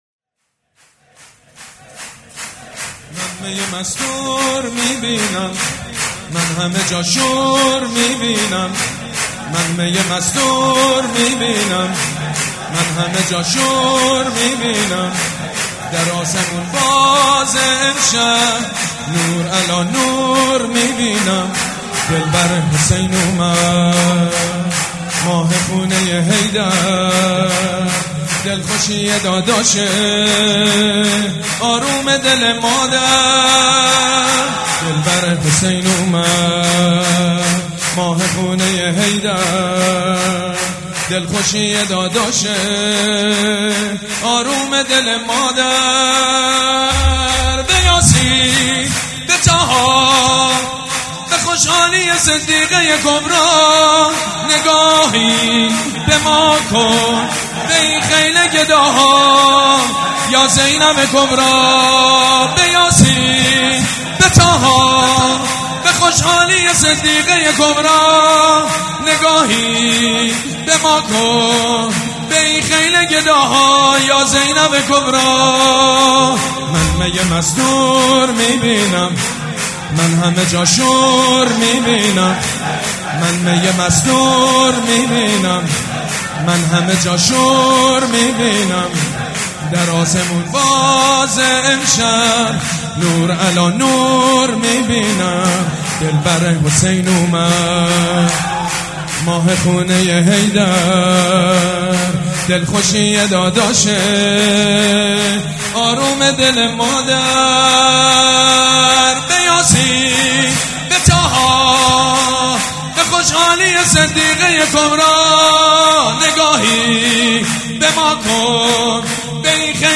شور
حاج سید مجید بنی فاطمه
ولادت حضرت زینب (س)